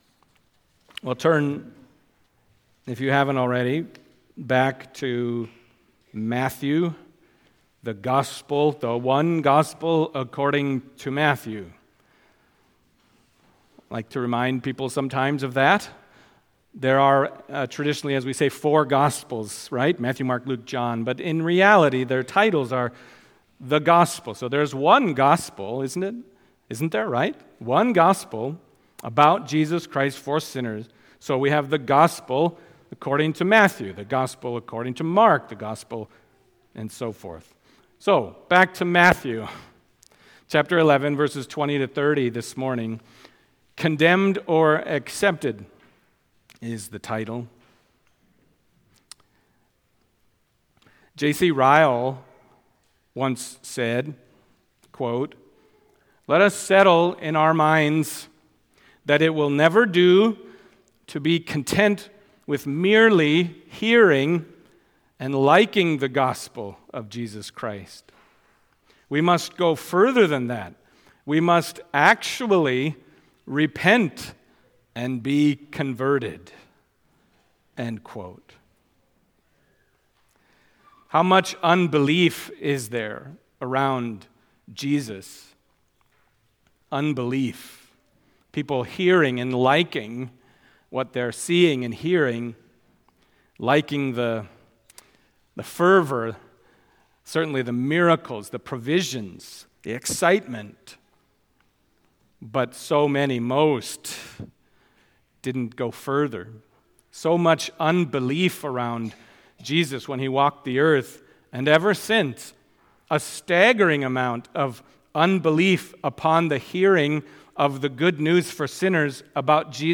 Matthew Passage: Matthew 11:20-30 Service Type: Sunday Morning Matthew 11:20-30 « Defending John the Baptist Profaning the Covenant